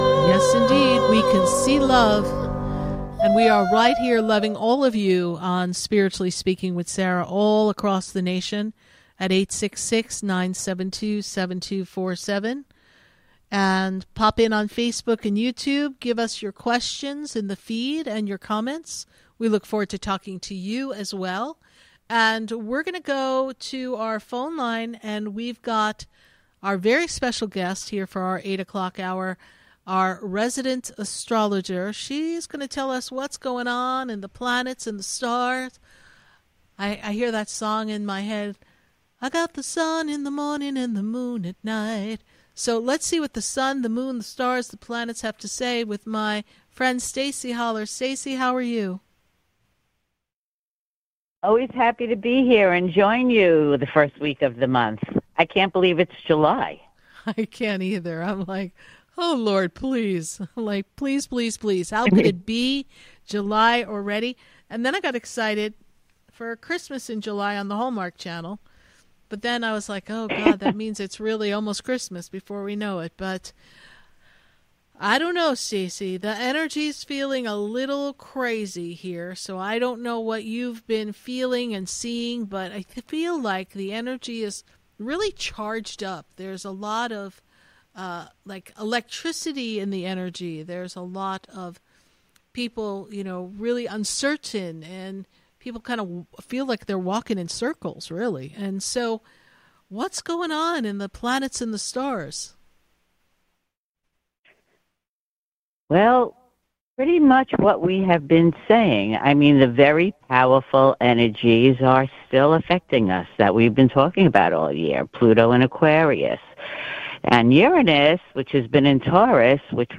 LIVE on the radio
monthly horoscope